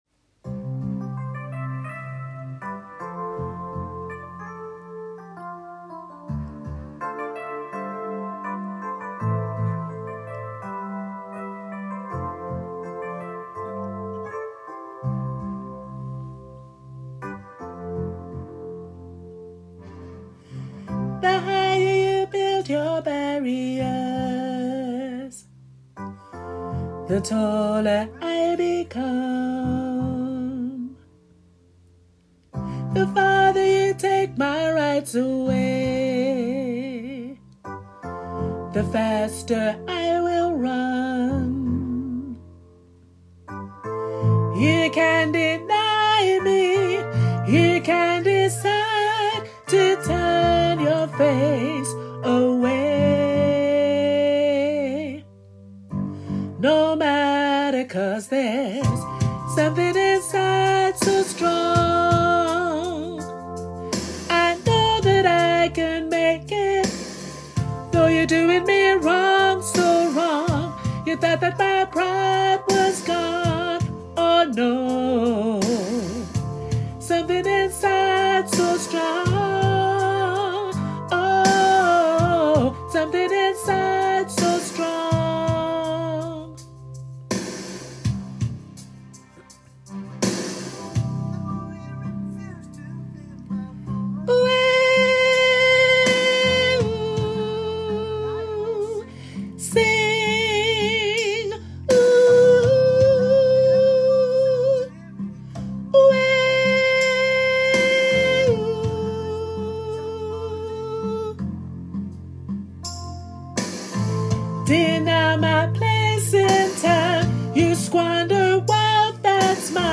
2026 – Soprano
Something-Inside-So-Strong-Sops.mp3